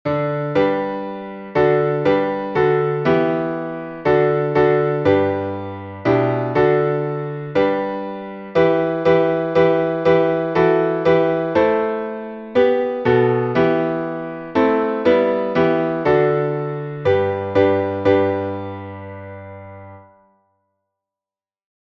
Сербский напев